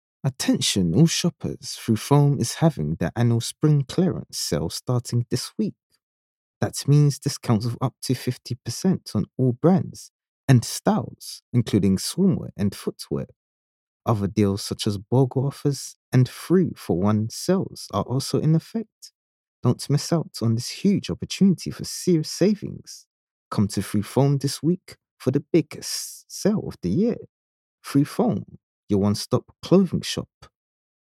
English (Caribbean)
Yng Adult (18-29) | Adult (30-50)